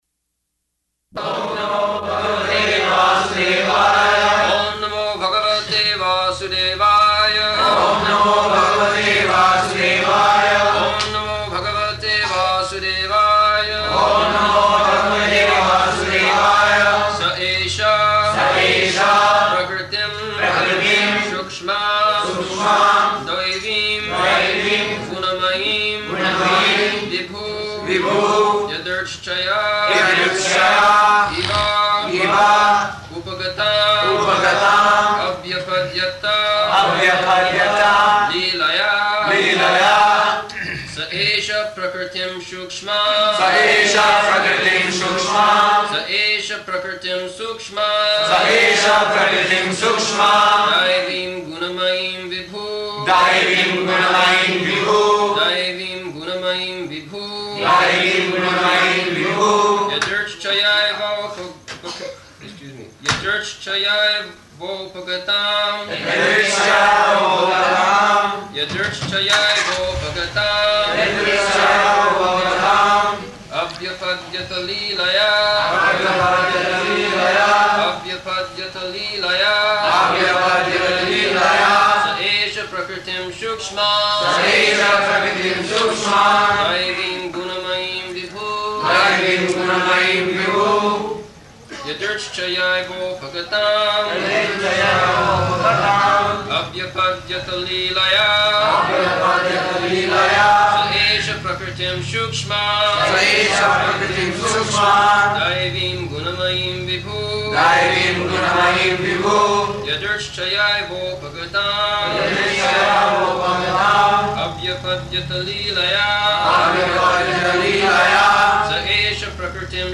December 16th 1974 Location: Bombay Audio file